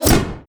EXPLOSION_Arcade_05_mono.wav